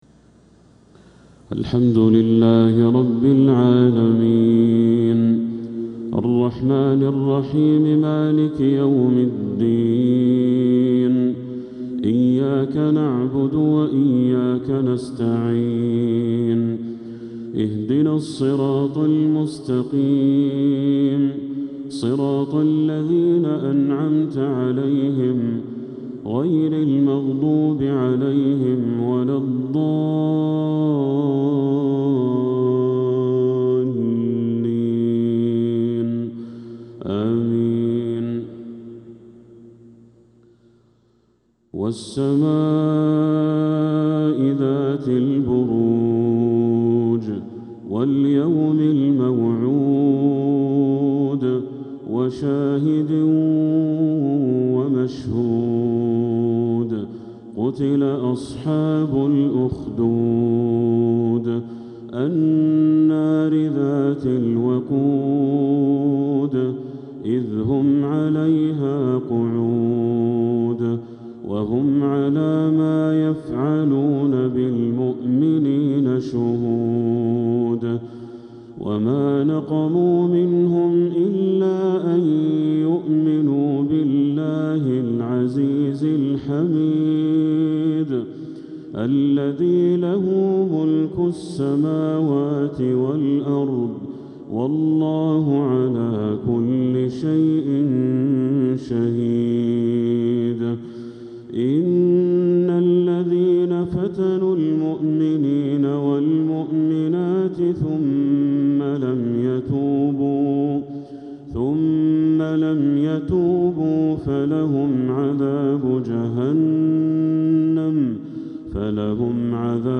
عشاء الأربعاء 14 محرم 1447هـ | سورة البروج كاملة | Isha prayer from Surah Al-Burooj 9-7-2025 > 1447 🕋 > الفروض - تلاوات الحرمين